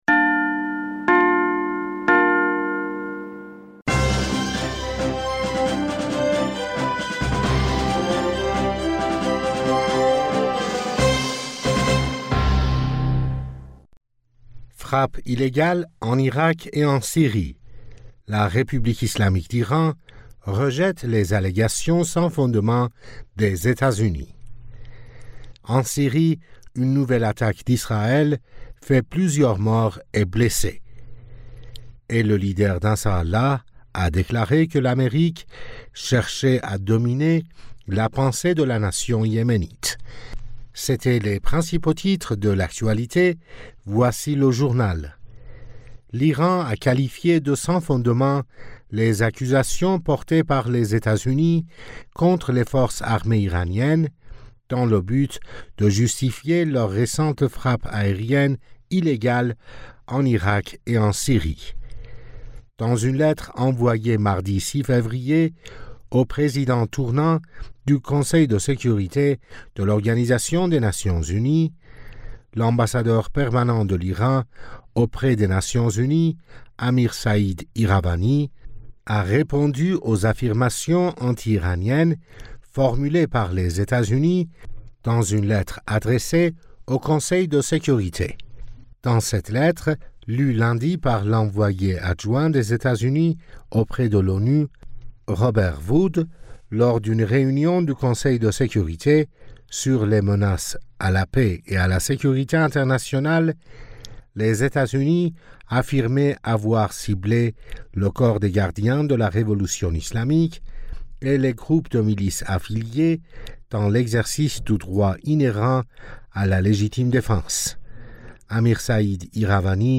Bulletin d'information du 07 Fevrier 2024